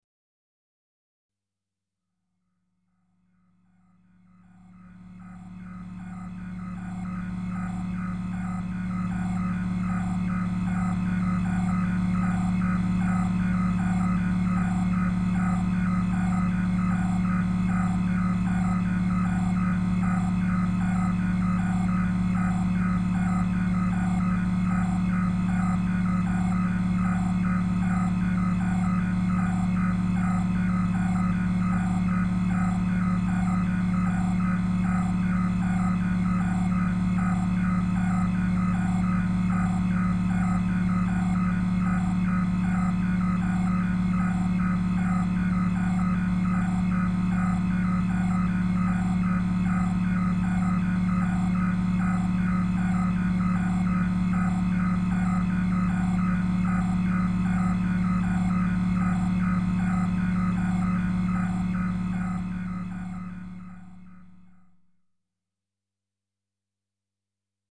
Электронные звуки: Компьютерная комната
elektronnie_zvuki_kompyuternaya_komnata_co2.mp3